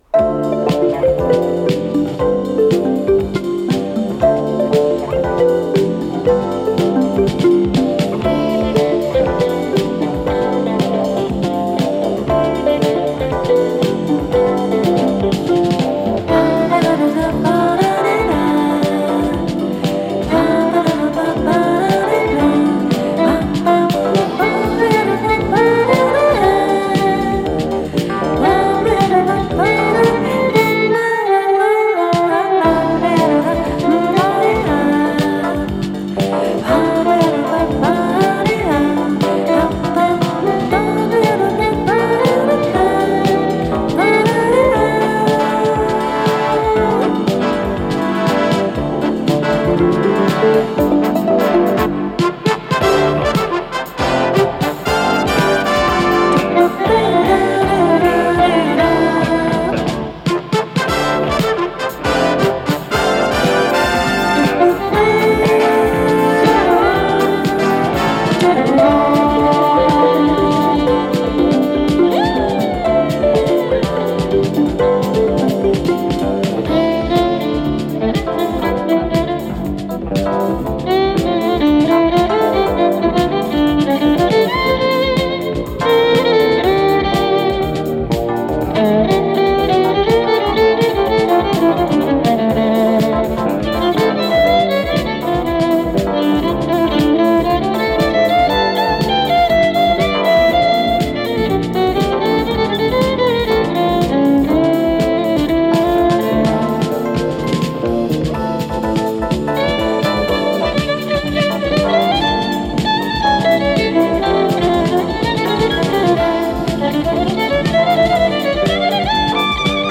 ПодзаголовокПьеса
ВариантДубль моно